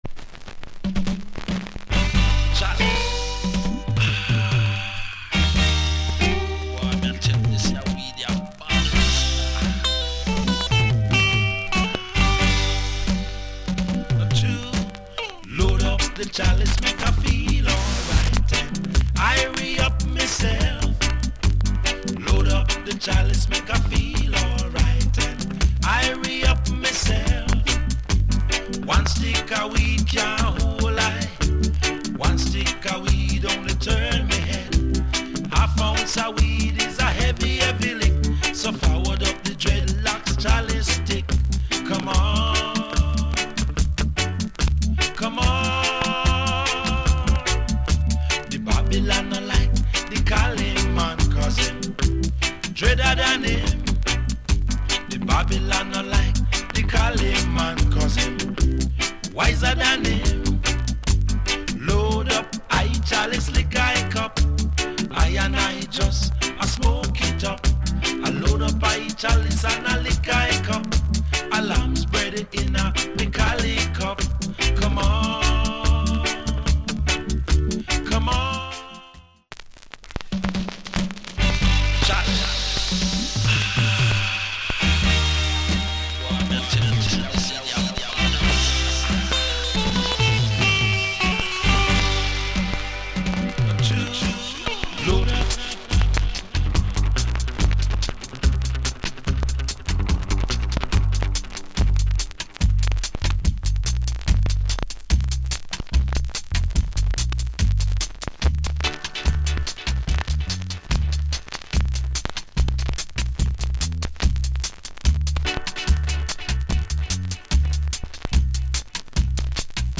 ** ヴォーカルが入ってきたところで一瞬スプレーノイズ入ります。